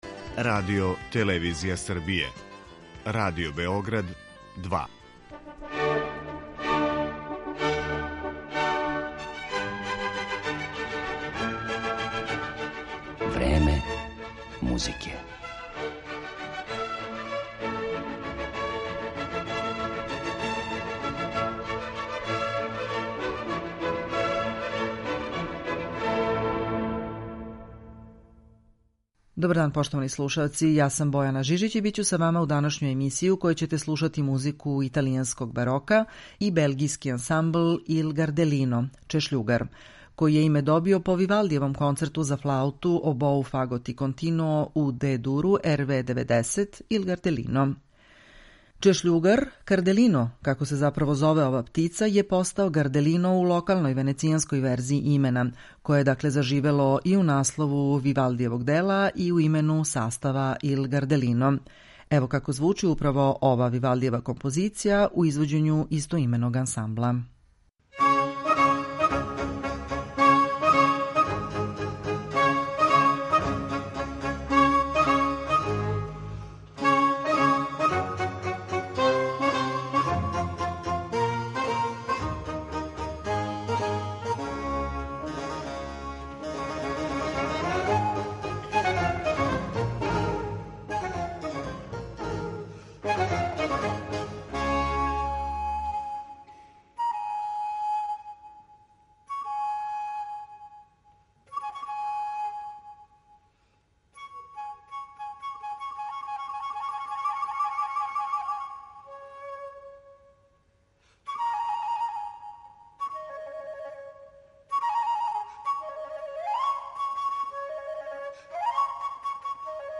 По Вивалдијевом концерту за флауту, обоу, фагот и континуо у Д-дуру, Il Gardellino (Чешљугар), добио је име изврсни белгијски ансамбл за рану музику који на веома вешт начин мири историјску веродостојност и жељу да звучи модерно.